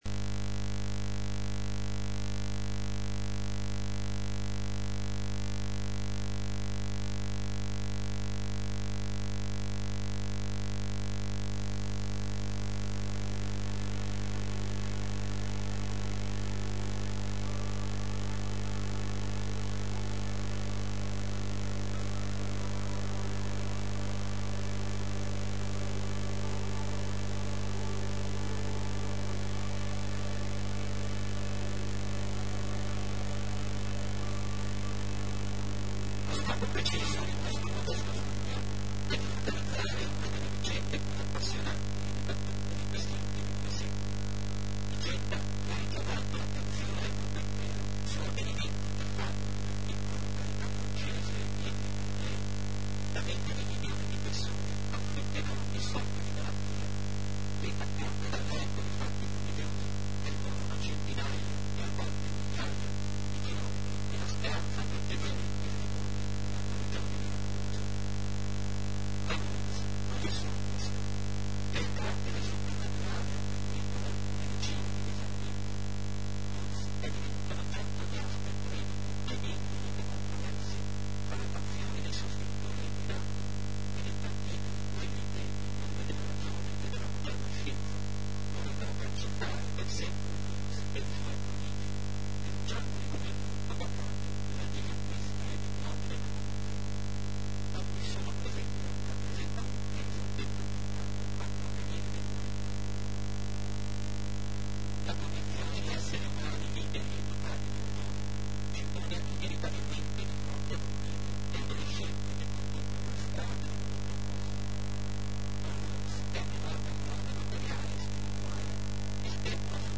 audioconferenza lourdes e i suoi miracoli